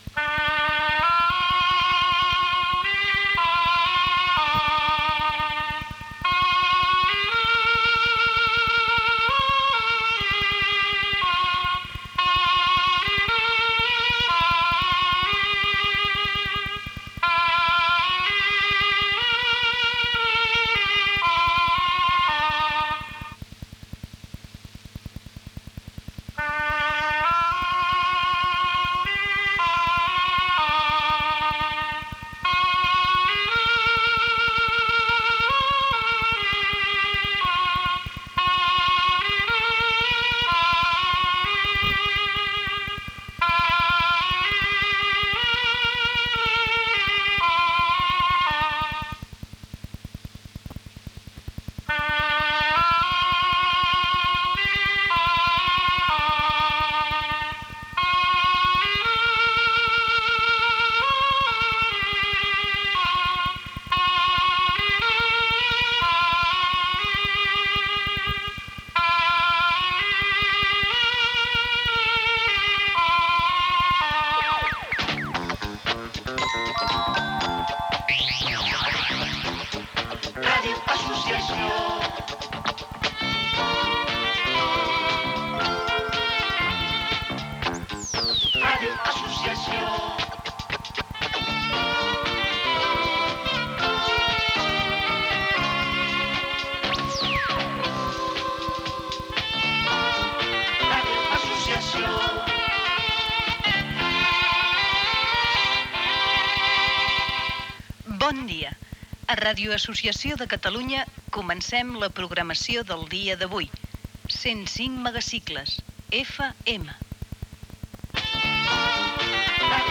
82581f6d11bec92c9fac84997a1e103b98ee775b.mp3 Títol Ràdio Associació RAC 105 Emissora Ràdio Associació RAC 105 Titularitat Pública nacional Descripció Sintonia i inici de l'emissió.